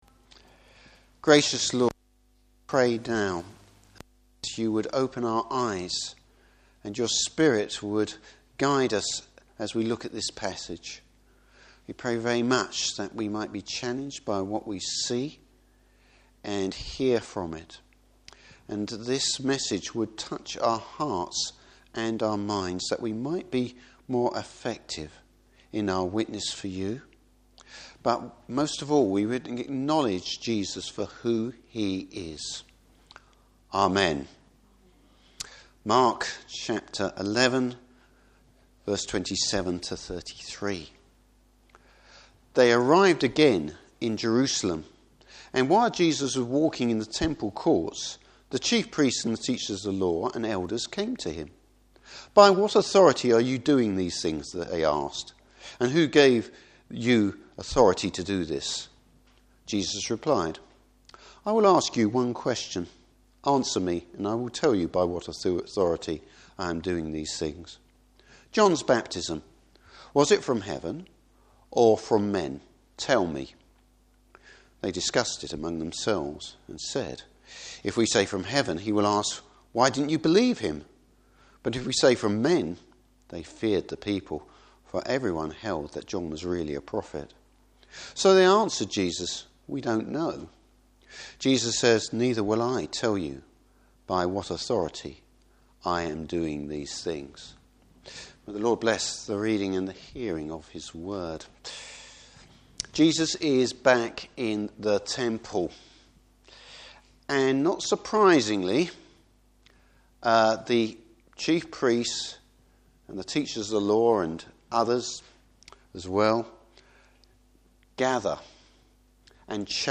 Service Type: Morning Service Who’s showing real authority?